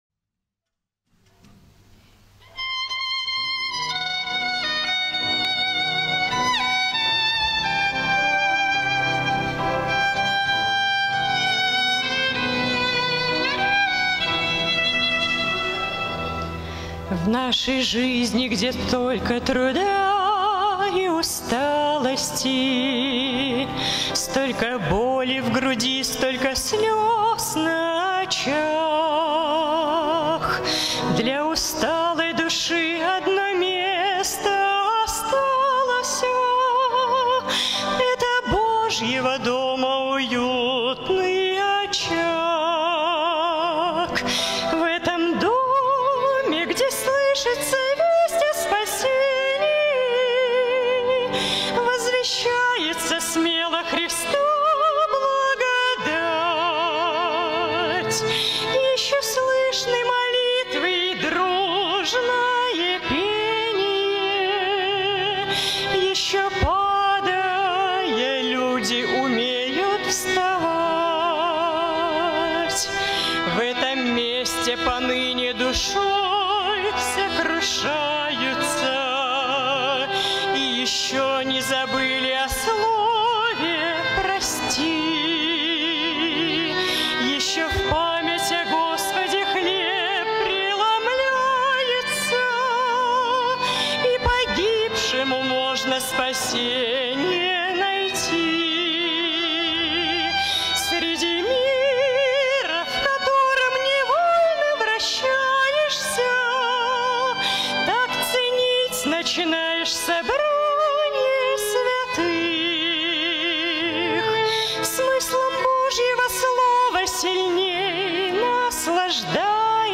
67 просмотров 68 прослушиваний 3 скачивания BPM: 80